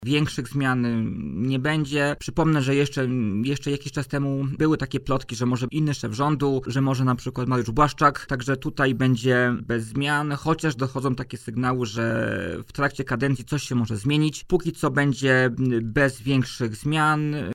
podkreślił na antenie Radia Warszawa w Poranku „Siódma9”